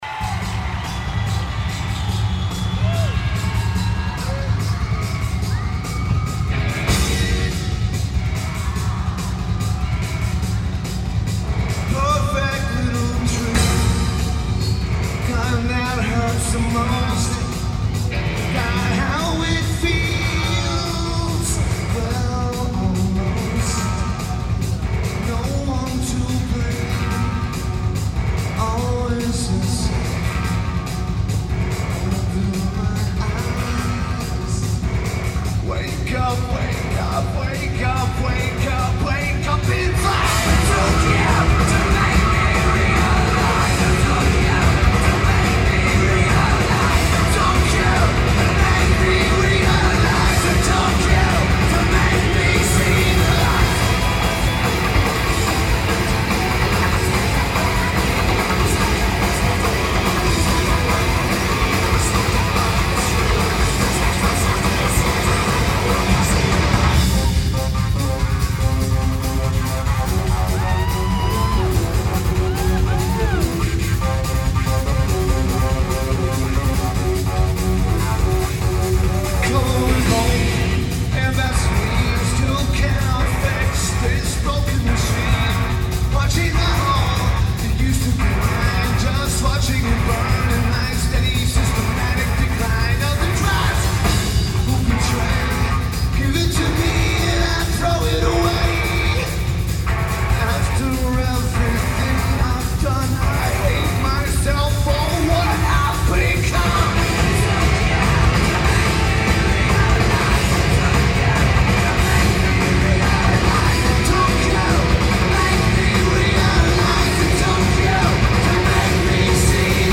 Cox Arena
Lineage: Audio - AUD (DPA 4061's + CSBox + Sony PCM-M1)